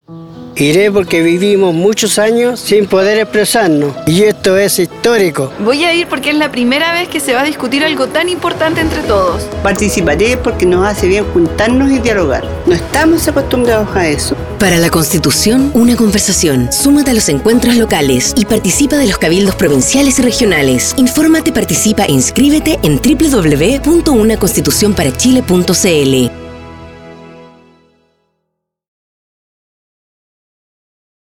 Audio con distintas voces testimoniales que llaman a la participación en el proceso constituyente, especialmente en los encuentros locales, cabildos provinciales y regionales.